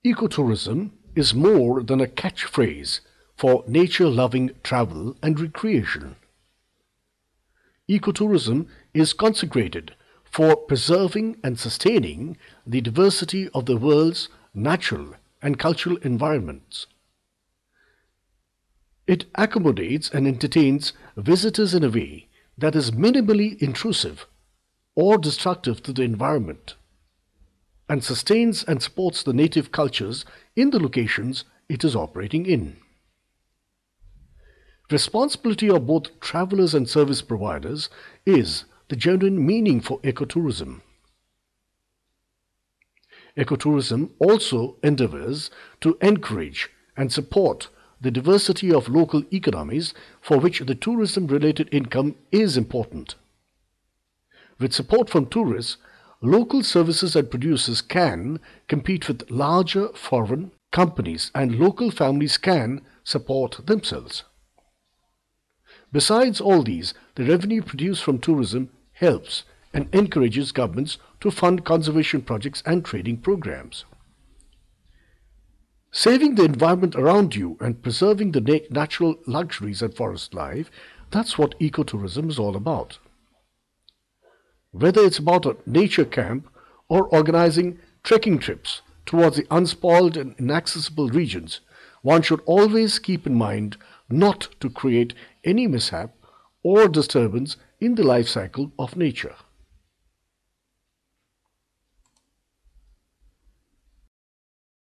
indisches engl.
Sprechprobe: Industrie (Muttersprache):